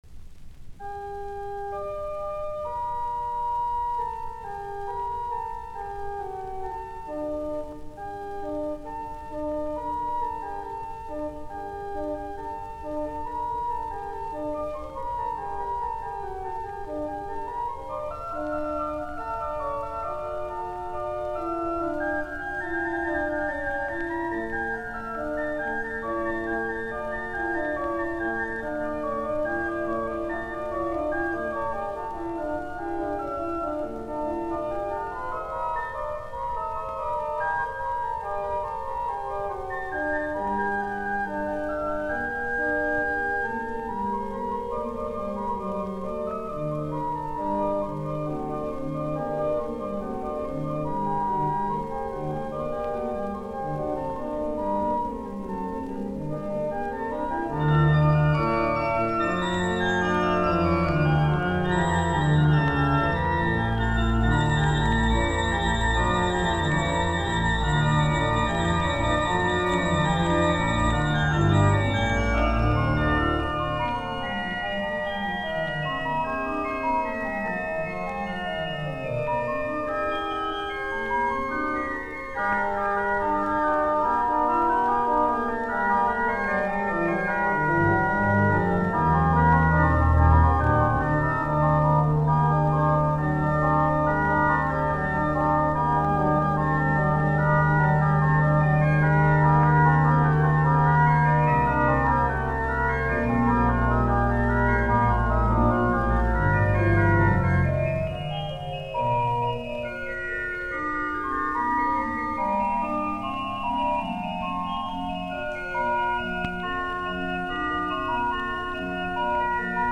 Fuugat, urut
Soitinnus: Urut